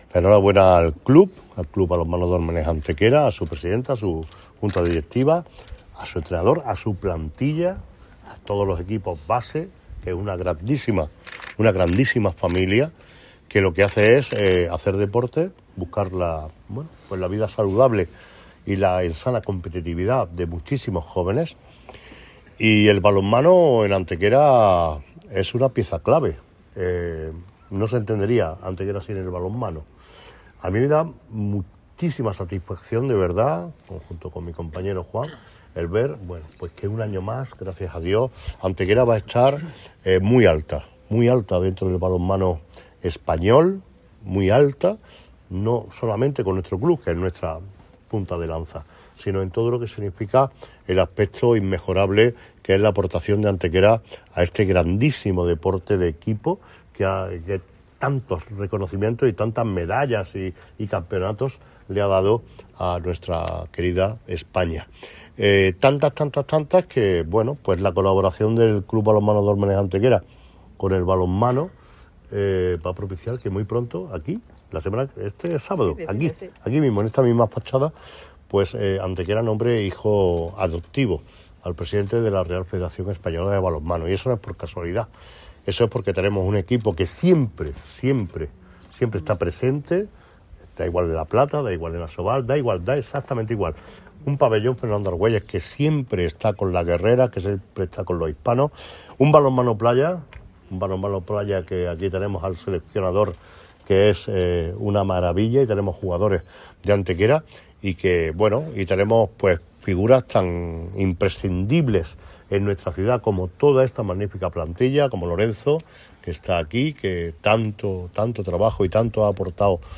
El alcalde Manolo Barón y el teniente de alcalde Juan Rosas acompañan al equipo antequerano en su puesta de largo ante la prensa con motivo del inicio de una nueva temporada deportiva, en la que el Ayuntamiento de Antequera volverá a ofrecer su colaboración como principal sponsor.
Cortes de voz